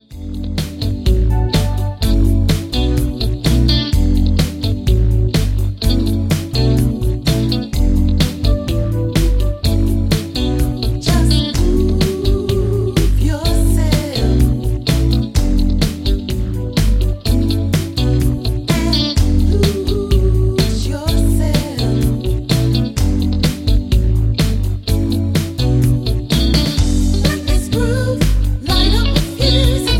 Backing track files: Disco (180)